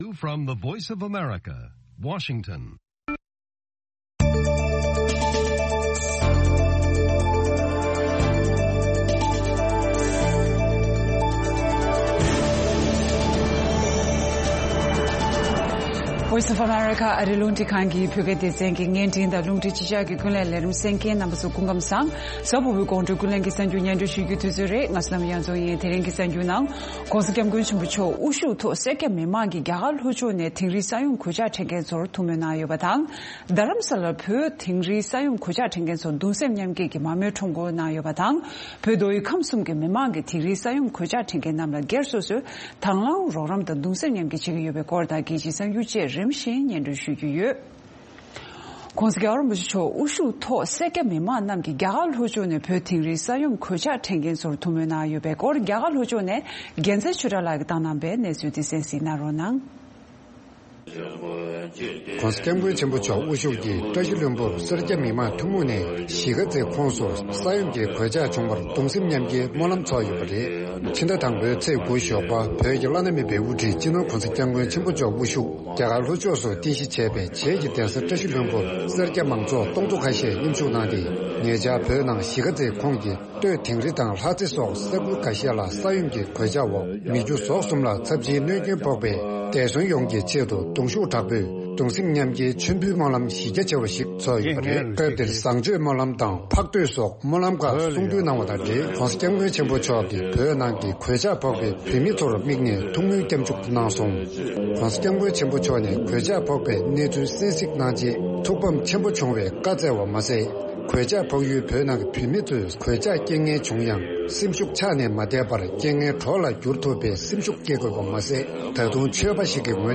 དགོང་དྲོའི་རླུང་འཕྲིན།
ཨ་རིའི་རླུང་འཕྲིན་ཁང་གི་ཀུན་གླེང་གསར་འགྱུར་ནང་༸གོང་ས་་མཆོག་དབུ་བཞུགས་ཐོག་བཀྲ་ཤིས་ལྷུན་པོར་བོད་ནང་ས་ཡོམ་གོད་ཆག་བྱུང་བར་སྨོན་ལམ་ཚོགས་པ། བཞུགས་སྒར་དུ་གདུང་སེམས་མཉམ་བསྐྱེད་དང་སྨོན་ལམ་མཚན་སྐྱོང་། བོད་ནང་དུ་གདུང་སེམས་མཉམ་བསྐྱེད་ཀྱི་རོགས་སྐྱོར། སྲིད་འཛིན་ཟུར་པ་ཁར་ཊར་ལ་མྱ་ངན་སོགས་ཡོད།